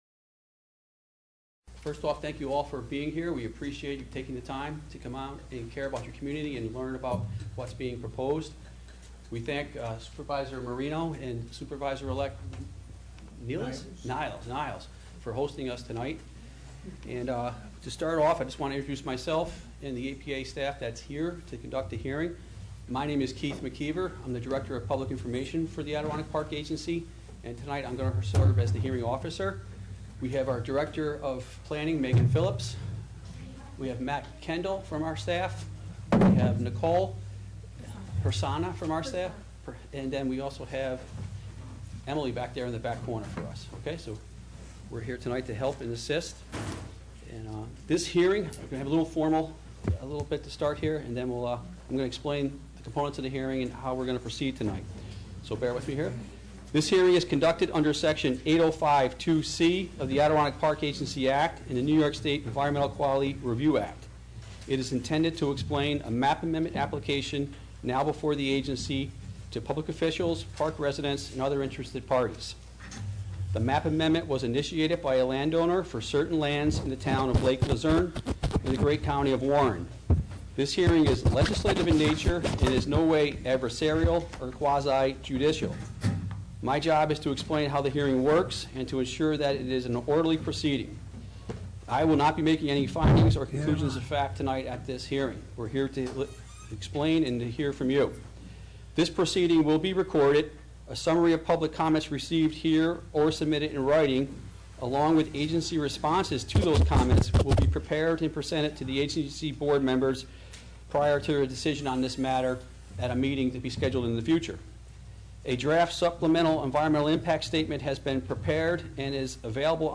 Public Hearing